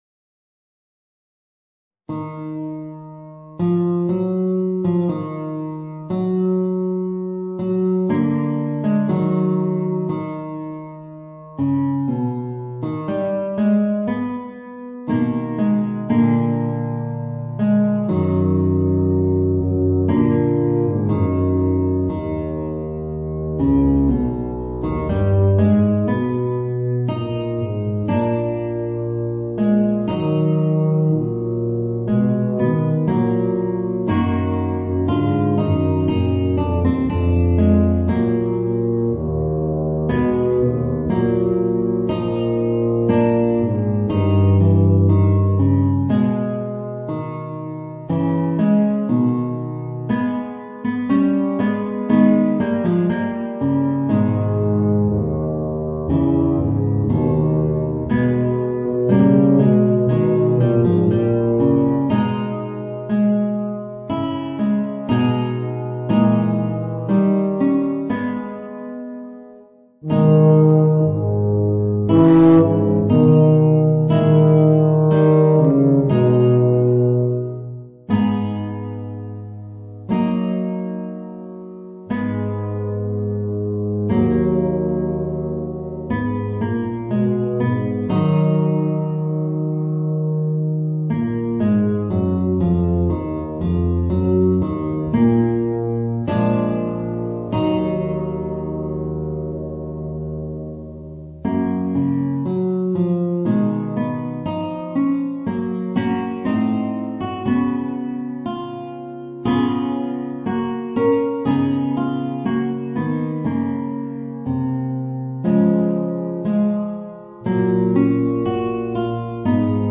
Voicing: Guitar and Bb Bass